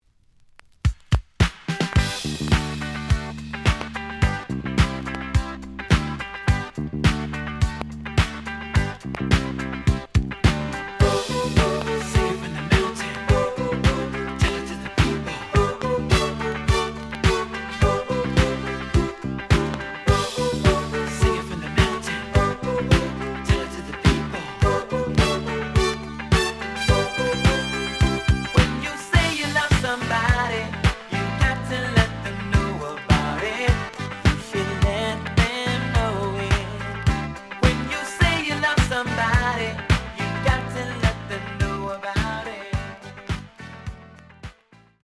The audio sample is recorded from the actual item.
●Genre: Disco
Some click noise on B side due to scratches.